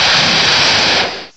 cry_not_kyurem.aif